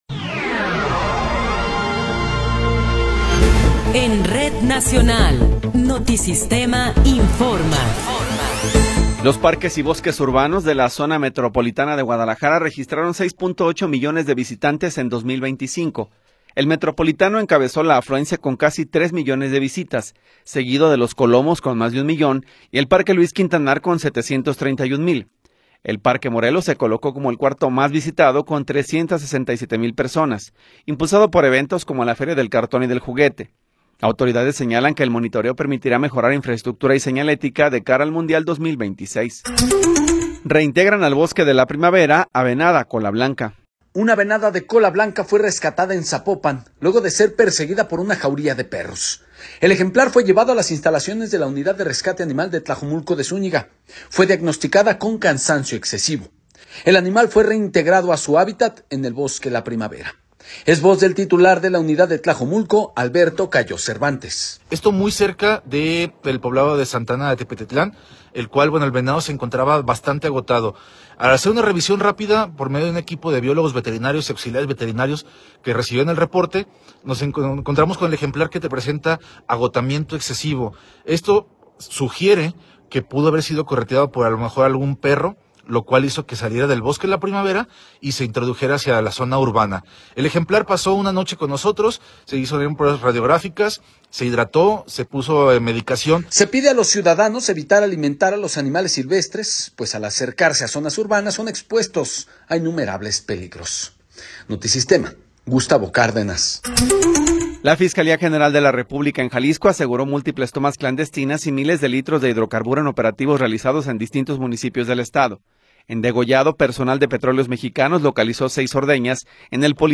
Noticiero 16 hrs. – 24 de Enero de 2026
Resumen informativo Notisistema, la mejor y más completa información cada hora en la hora.